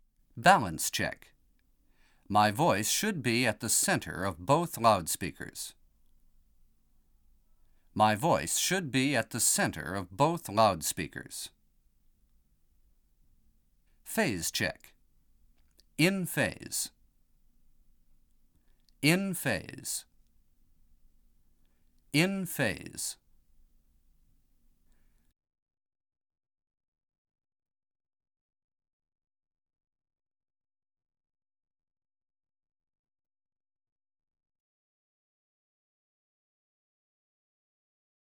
밸런스 및 위상 테스트.mp3